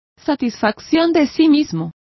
Complete with pronunciation of the translation of complacence.